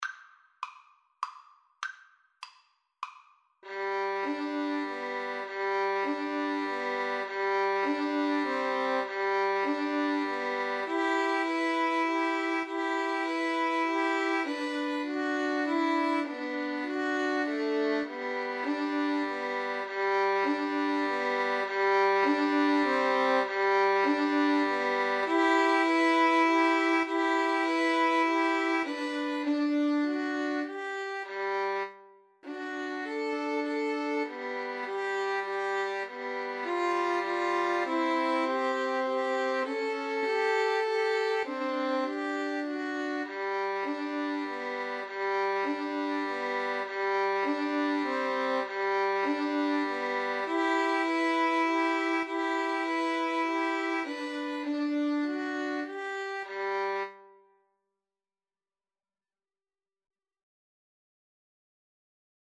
It is written in a simple waltz style.
Moderato
Violin Trio  (View more Easy Violin Trio Music)
brahms_waltz_3VLN_kar1.mp3